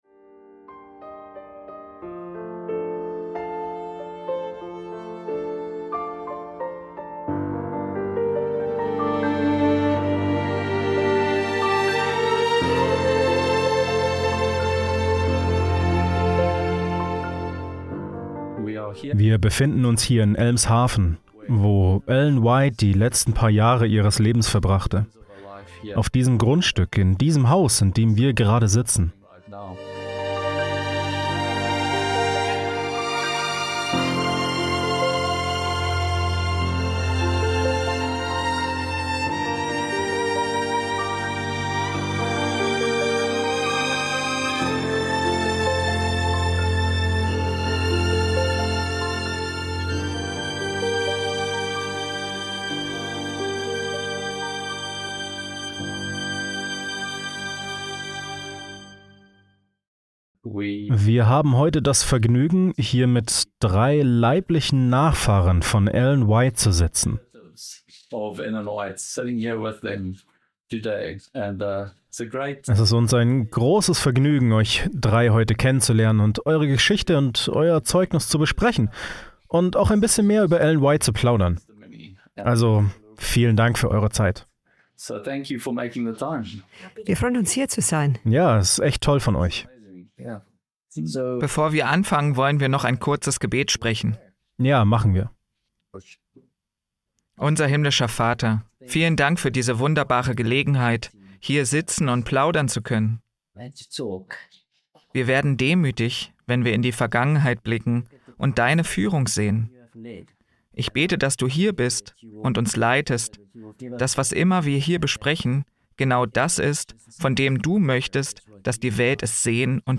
Was von Ellen White geblieben ist - Ein Gespräch mit ihren Ururenkelinnen über ihr Vermächtnis und Leben